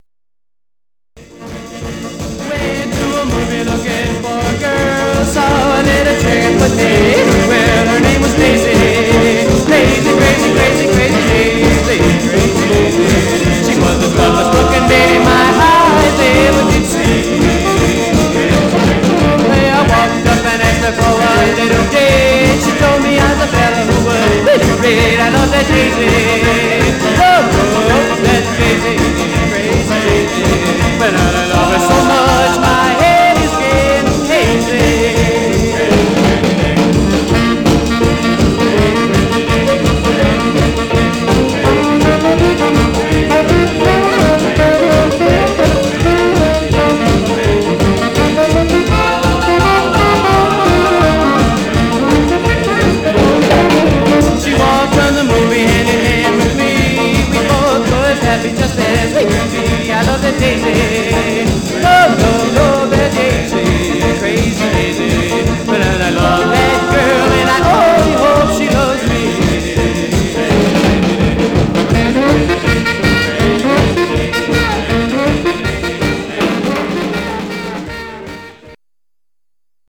Surface noise/wear
Mono
Rockabilly